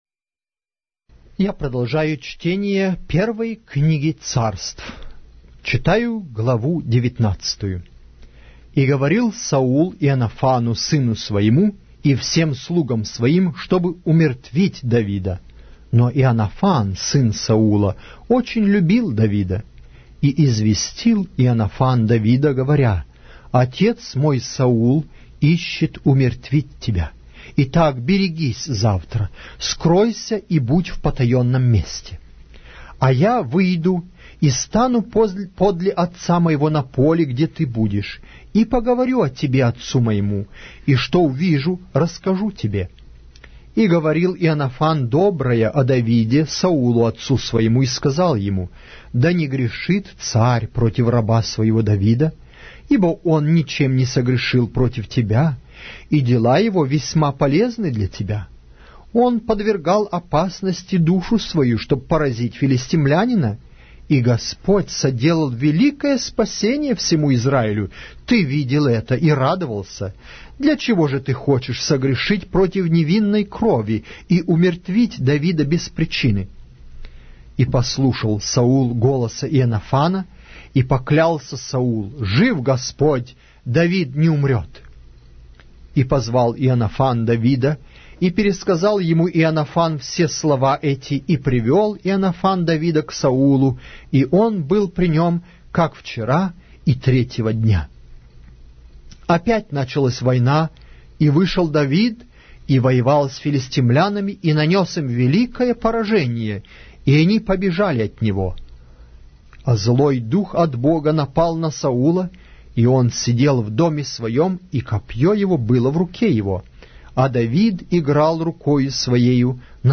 Глава русской Библии с аудио повествования - 1 Samuel, chapter 19 of the Holy Bible in Russian language